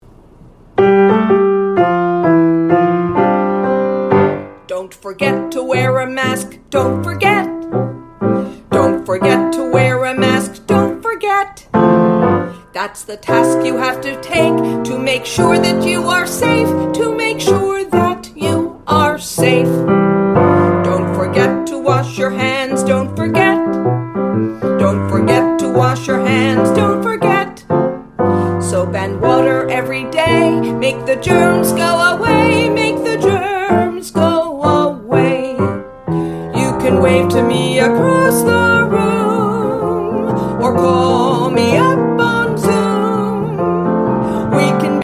▪ The full-length music track with vocals.